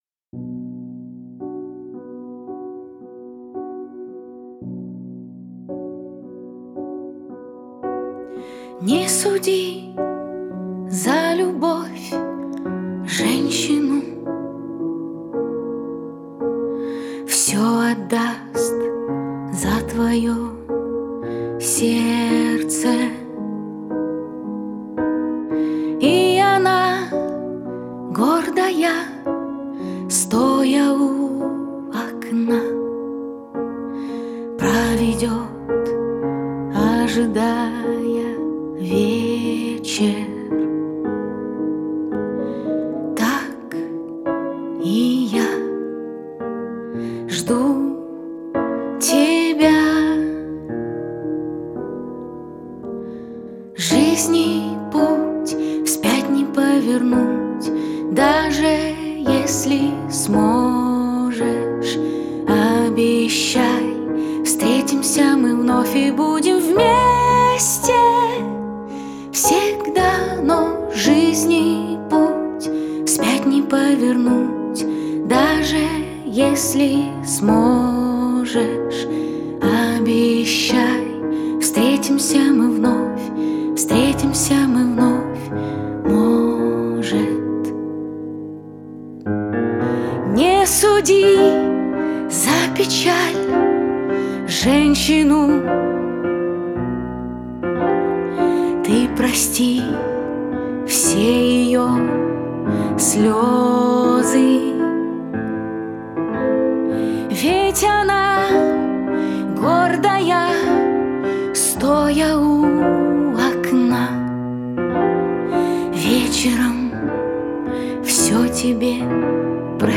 Русский романс…